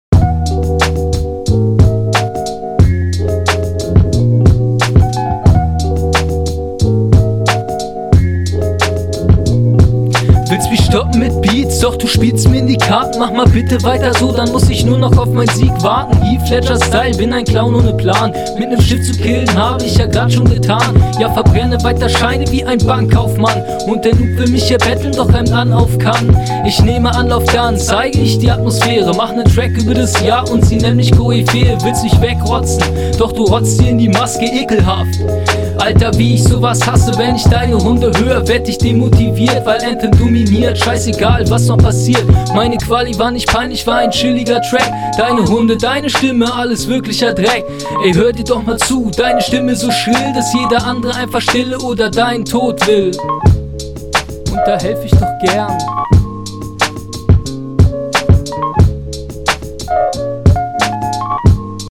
Sound finde ich bei dir besser, passt besser zu dem Beat.